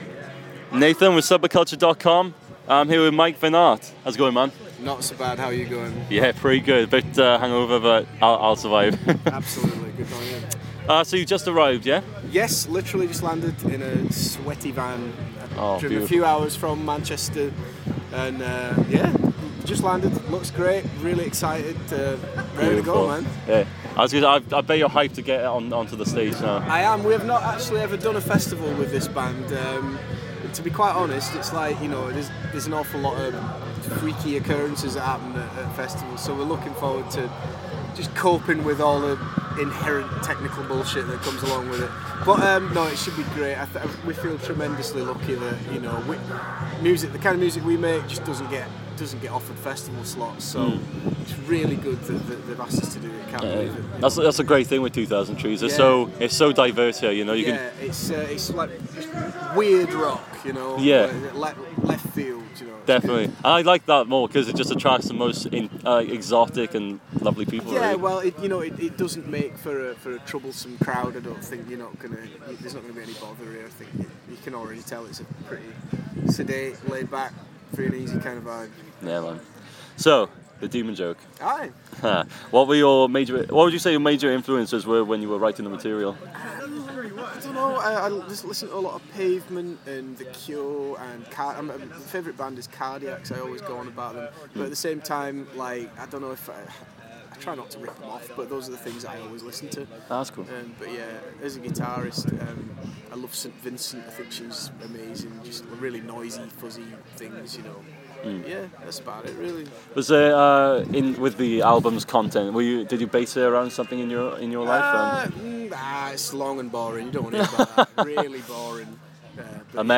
Vennart interview
Mike Vennart's interview with Subba-Cutcha at 2000 trees festival 2015